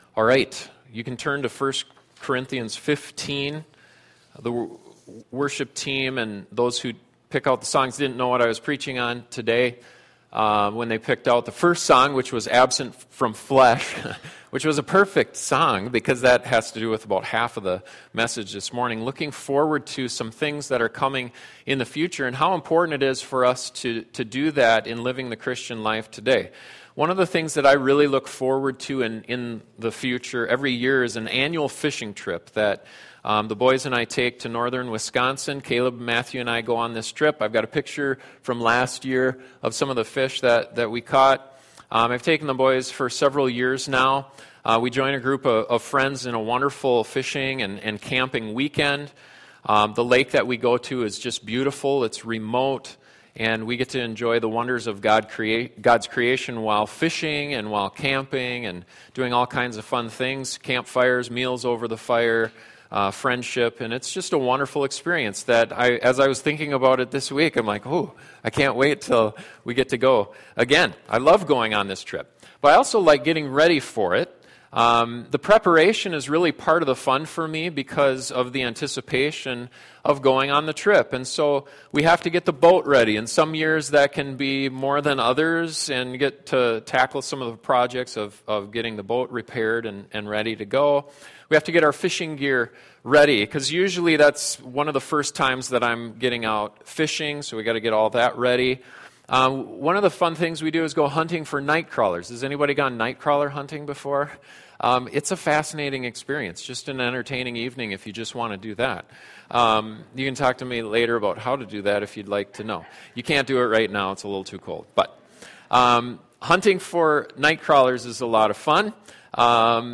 Life is tough sometimes and we need things to look forward to that can give us hope and anticipation. The second coming of Christ is the greatest thing that will happen to us and this sermon looks at two things we have to look forward to.